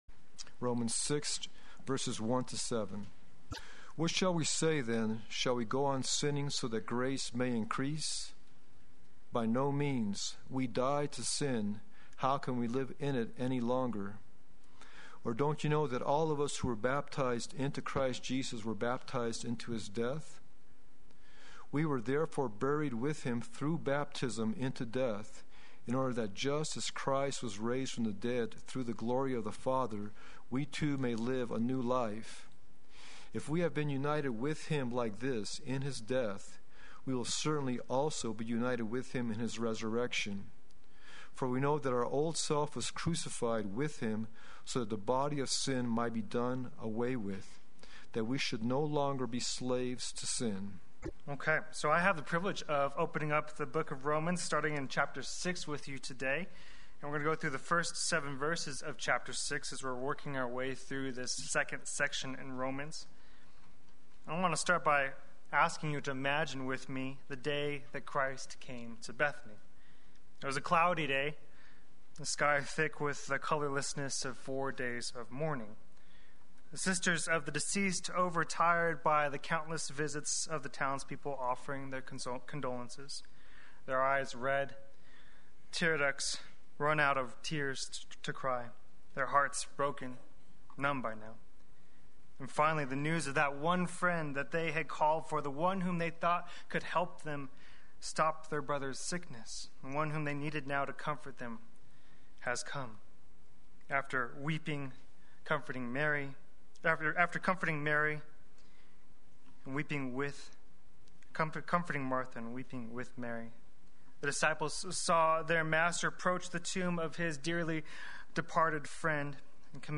Play Sermon Get HCF Teaching Automatically.
Dead and Alive Sunday Worship